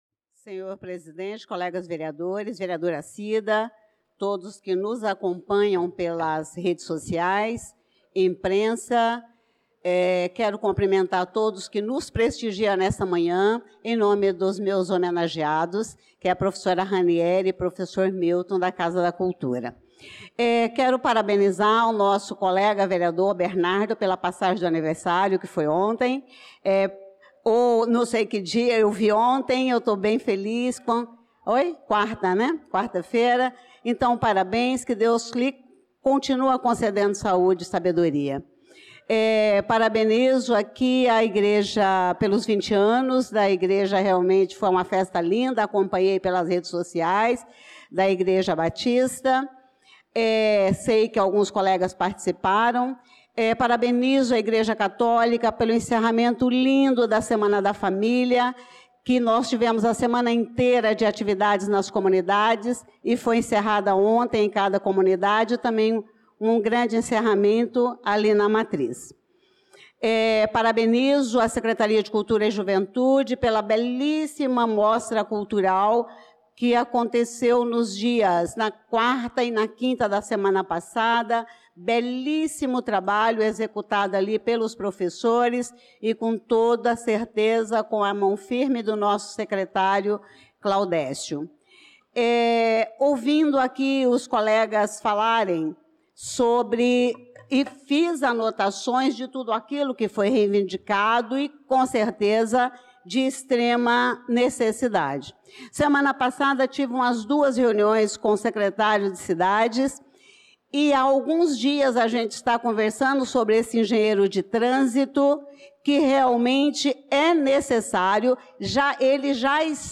Pronunciamento da vereadora Elisa Gomes na Sessão Ordinária do dia 18/08/2025.